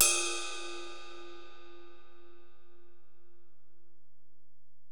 Index of /kb6/Alesis_DM-PRO/HiHat
HiHat_Region_119.wav